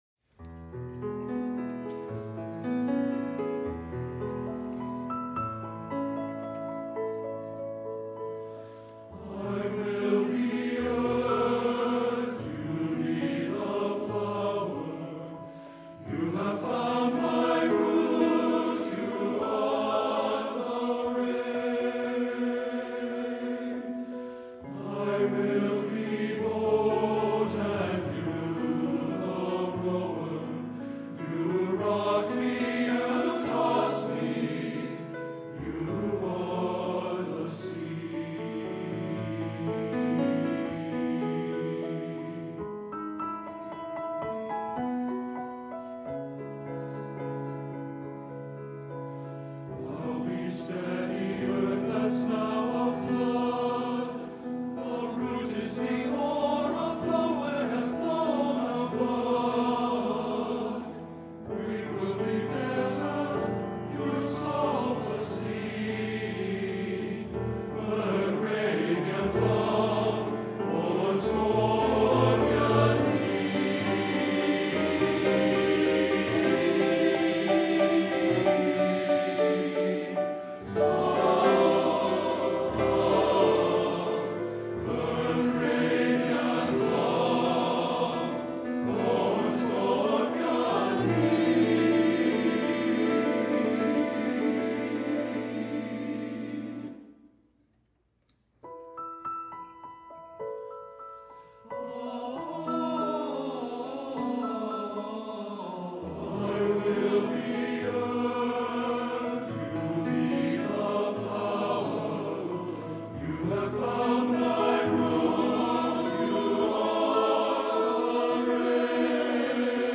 a MP3 file of the TTBB and piano version of this work performed by the River City Men's Chorus
iwillbeearth-ttbb.mp3